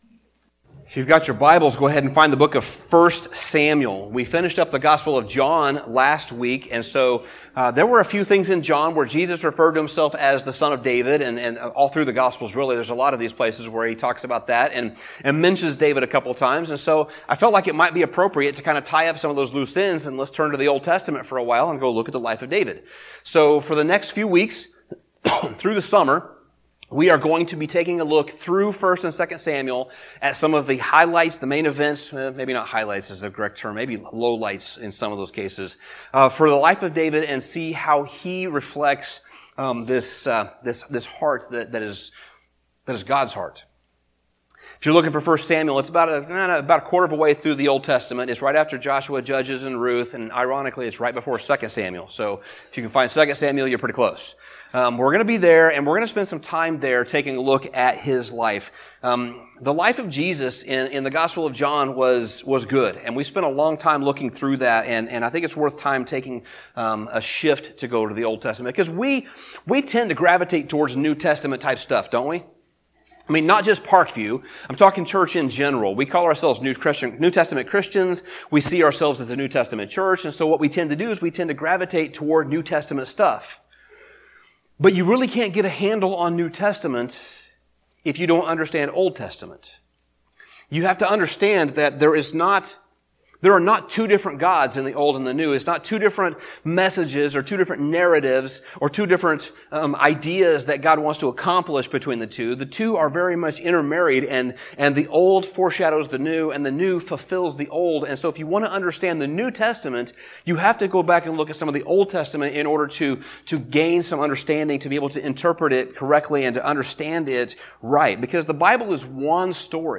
Sermon Summary In order to examine the life of David, we need to start at the beginning.